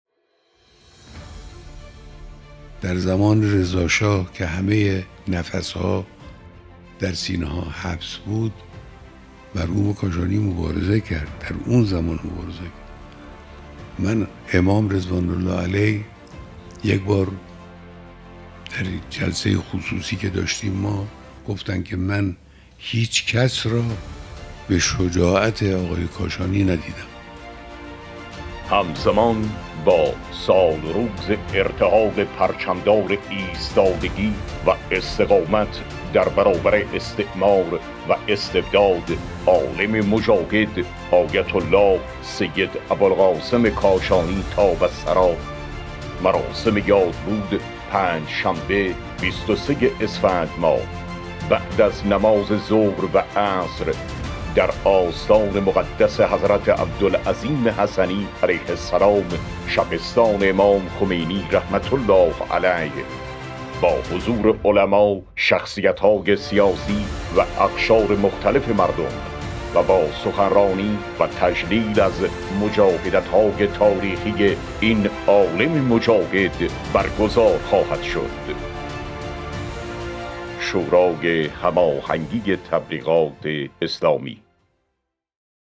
تیزر اطلاع رسانی یادبود آیت الله سید ابوالقاسم کاشانی